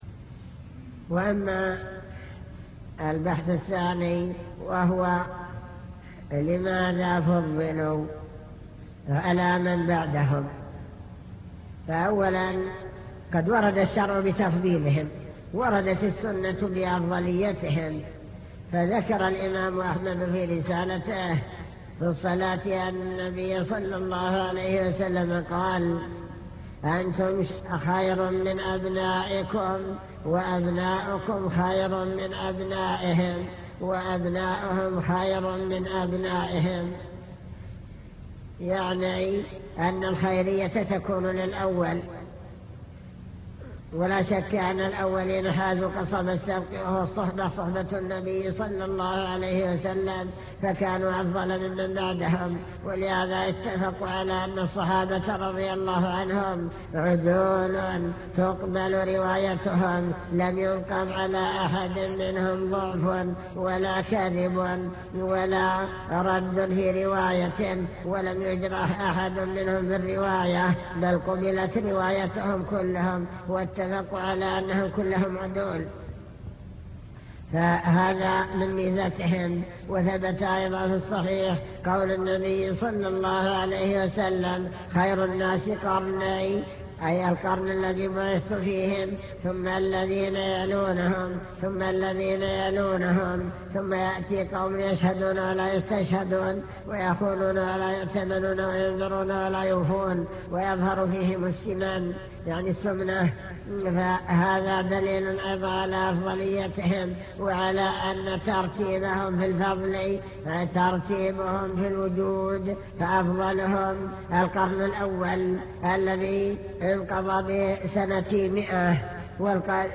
المكتبة الصوتية  تسجيلات - محاضرات ودروس  السلف الصالح بين العلم والإيمان